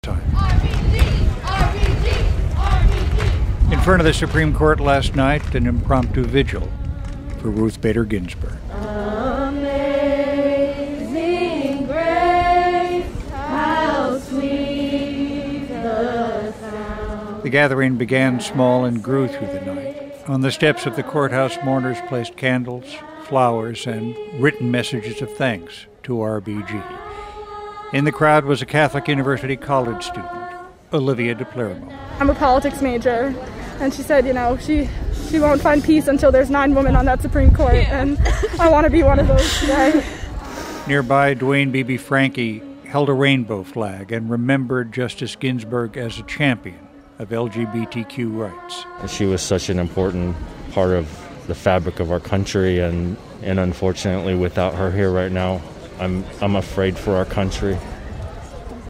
Mourners Spontaneously Gather Outside Supreme Court To Honor Ruth Bader Ginsburg